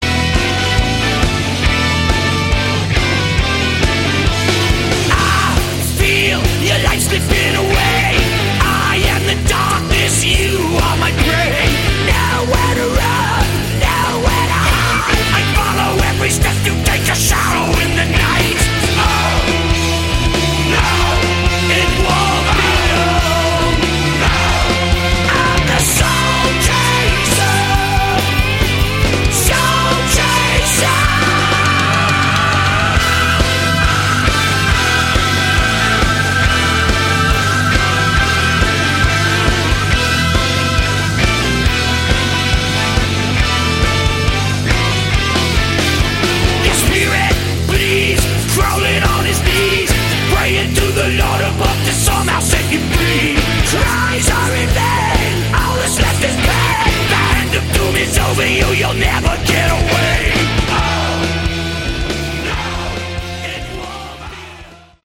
Category: Melodic Metal
all vocals and instruments